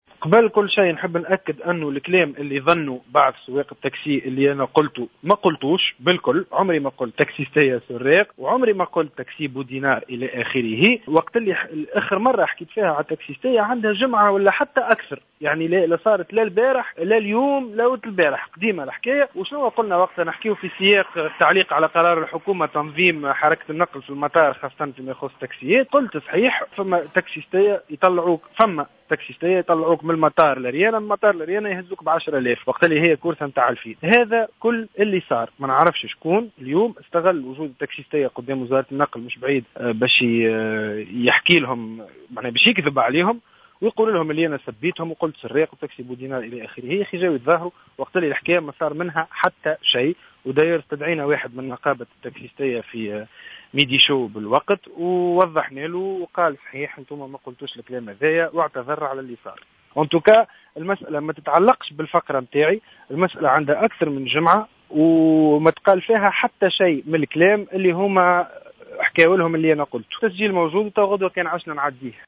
نفى الإعلامي هيثم المكي في تصريح لجوهرة أف أم اليوم الخميس 16 أفريل 2015 أن يكون قد نعت سائقي التاكسي بنعوت مهينة خلال فقرة قدمها في برنامج ميدي شو على إذاعة موزاييك.